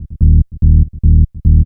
BL 145-BPM 1-D#.wav